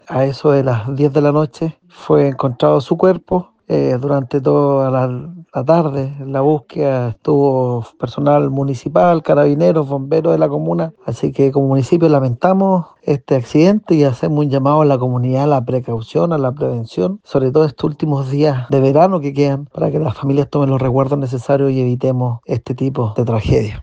Por su parte, el alcalde de El Carmen, Renán Cabezas, lamentó el accidente y también hizo un llamado a tener precaución al vacacionar.